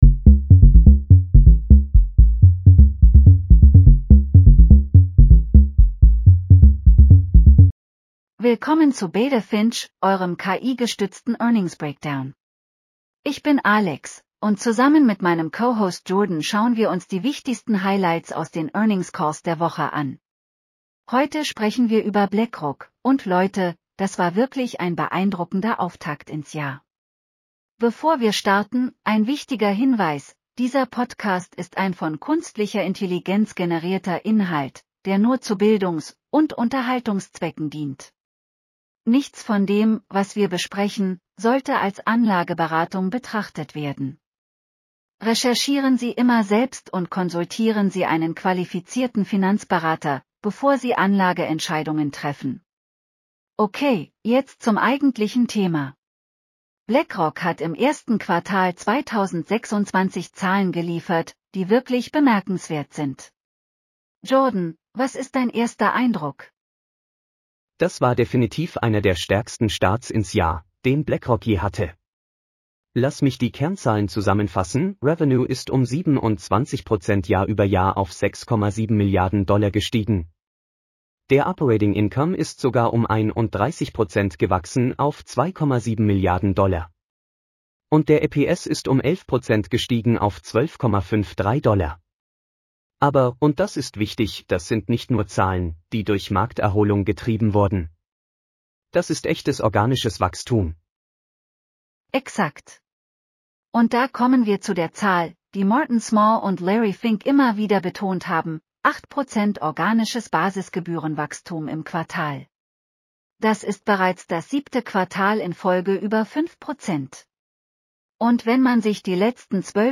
Willkommen zu Beta Finch, eurem KI-gestützten Earnings-Breakdown!